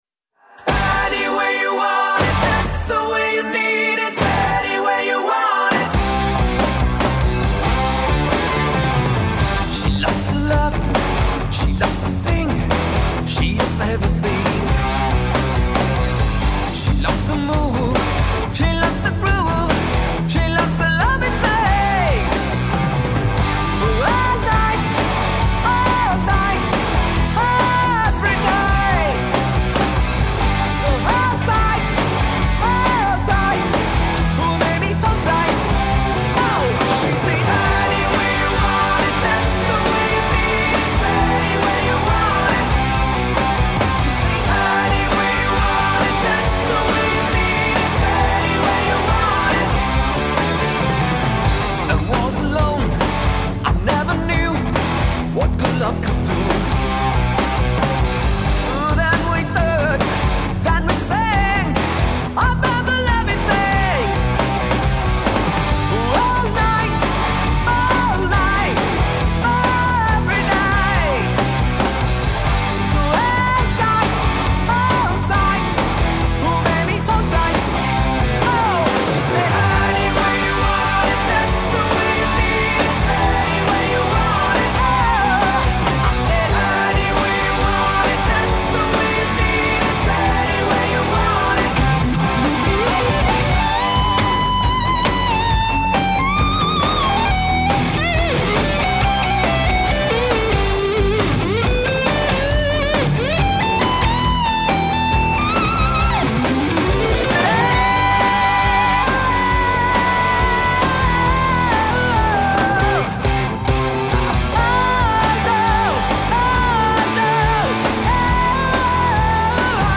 Keyboards & Vox
Bass & Backing vocals
Drums